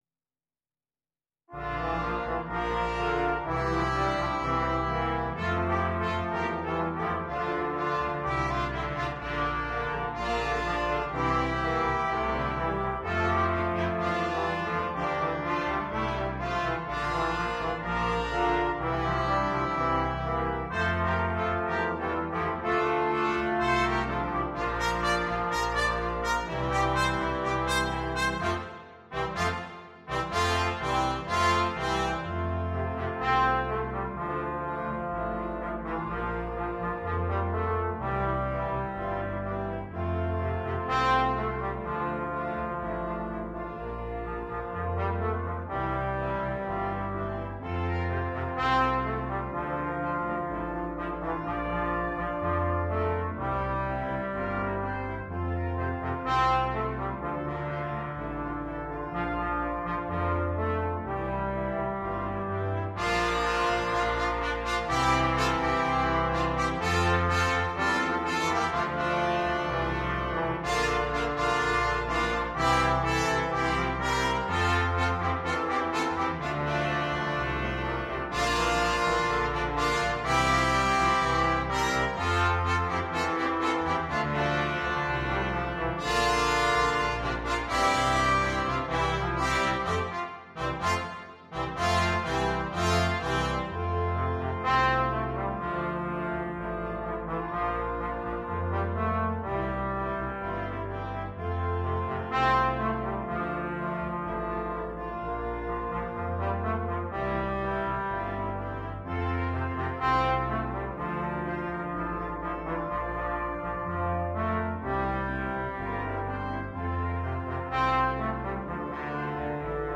Ноты для брасс-квинтета